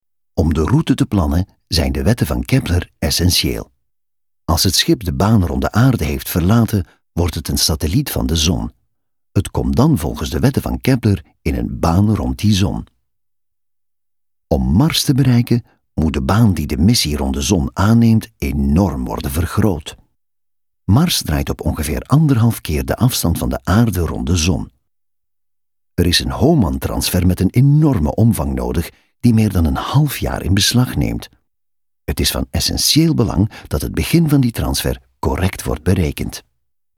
Distinctive, Mature, Warm
Explainer